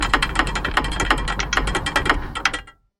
Gears Turning
Interlocking metal gears meshing and turning with rhythmic clicking and smooth rotation
gears-turning.mp3